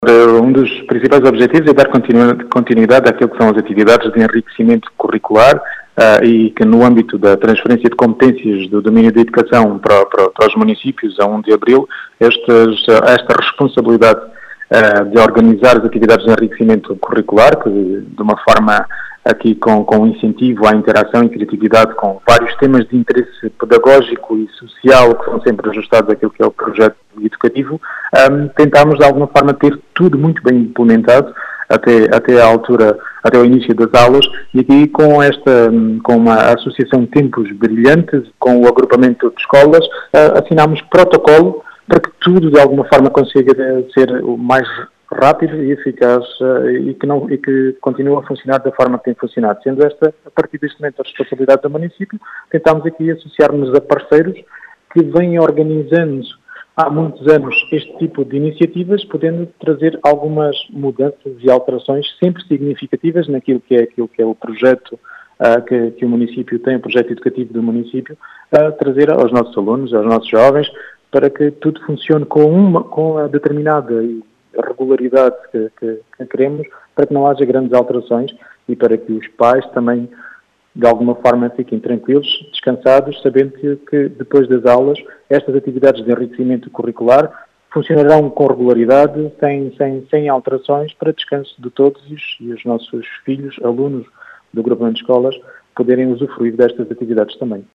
As explicações são de Rui Raposo, presidente da Câmara de Vidigueira.